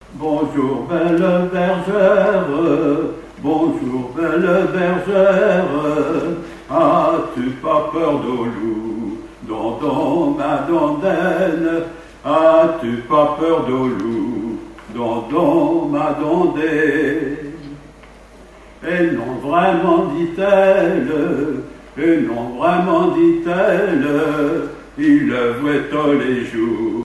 Festival du chant traditionnel
Pièce musicale inédite